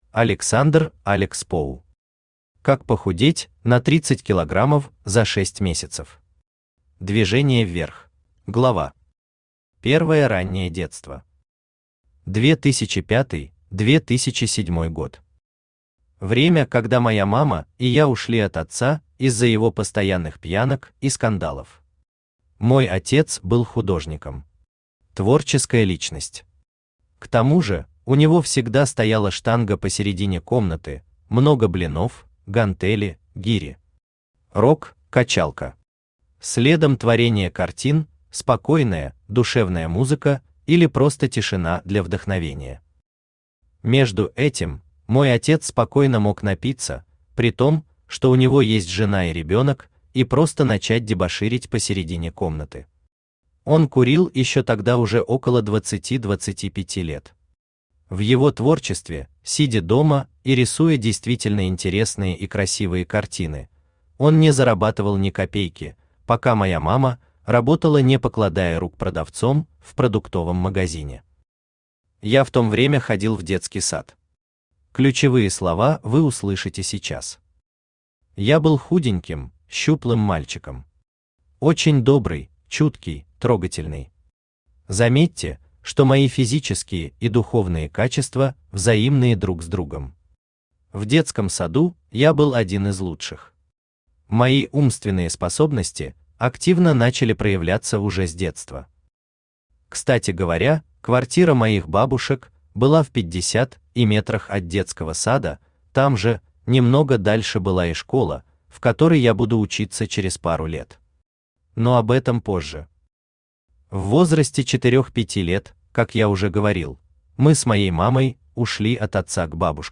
Аудиокнига Как похудеть на 30 кг за 6 месяцев. Движение вверх | Библиотека аудиокниг
Движение вверх Автор Александр Олегович Alex Po Читает аудиокнигу Авточтец ЛитРес.